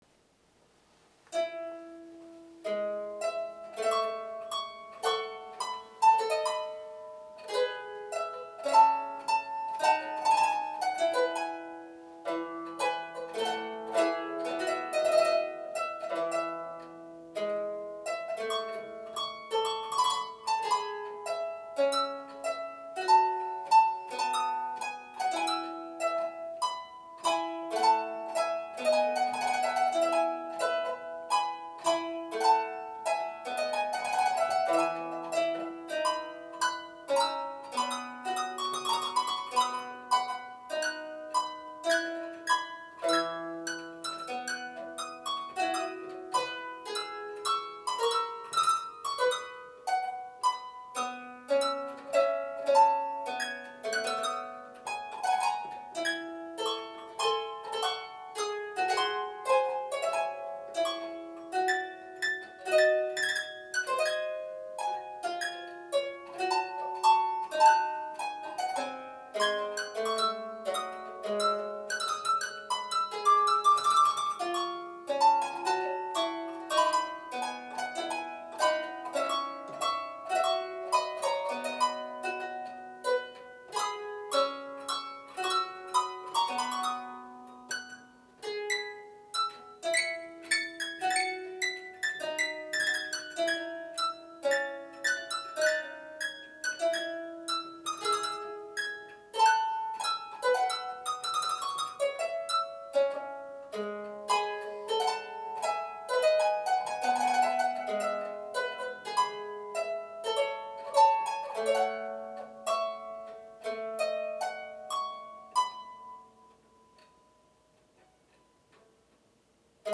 3' Ottavino Lautenwerk - 2x4' featuring an unprecedented action consisting of one rank of jacks plucking one or both choirs with single plectra.